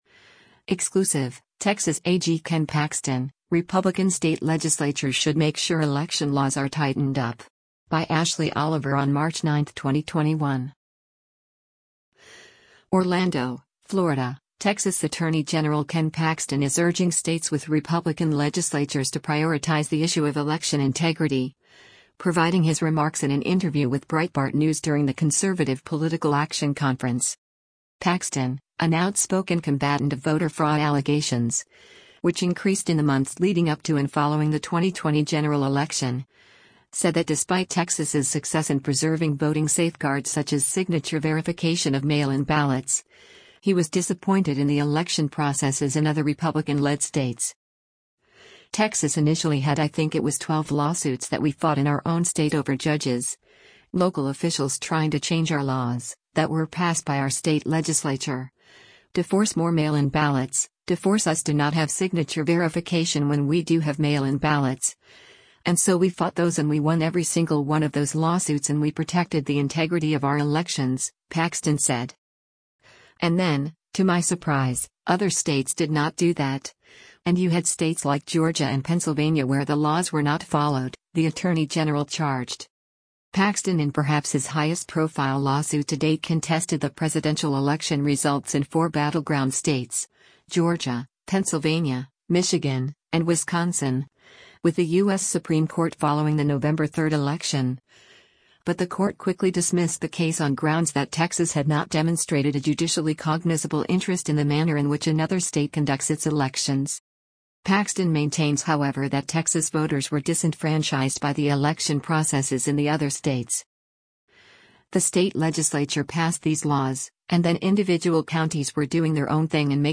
ORLANDO, Florida — Texas Attorney General Ken Paxton is urging states with Republican legislatures to prioritize the issue of election integrity, providing his remarks in an interview with Breitbart News during the Conservative Political Action Conference.